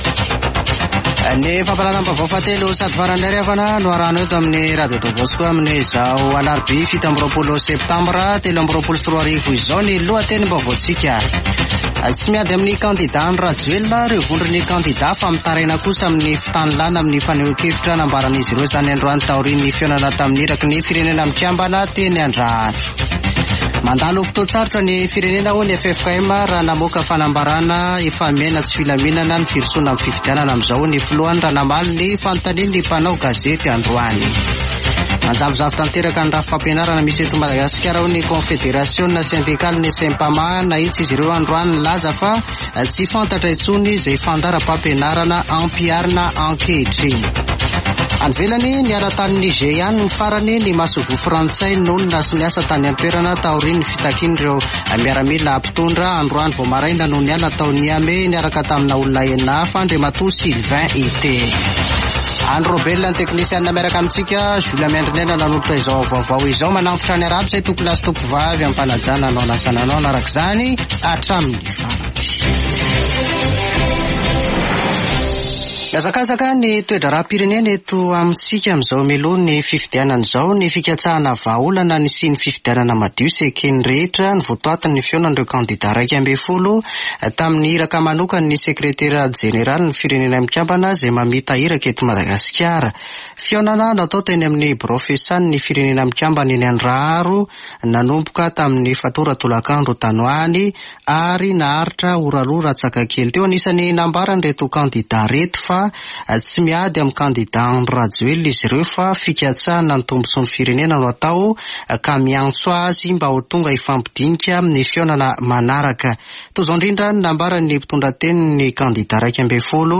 [Vaovao hariva] Alarobia 27 septambra 2023